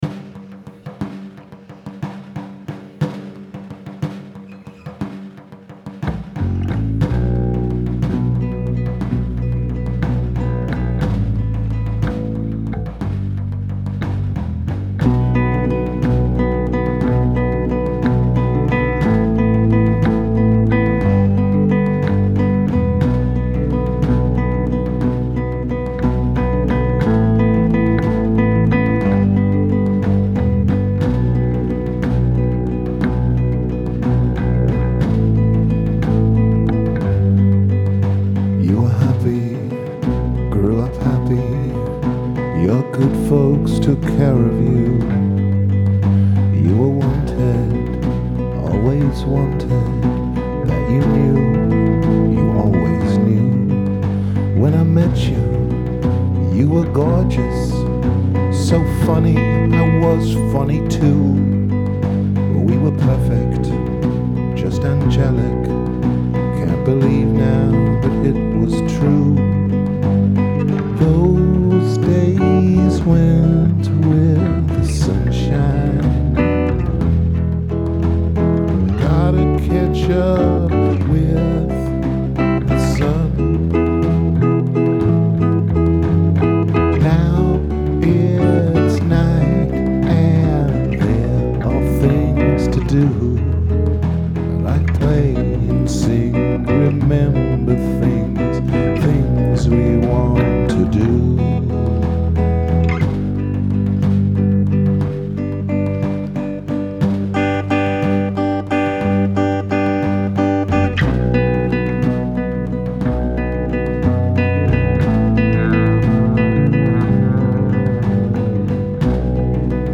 The Available
Rehearsals 18.2.2012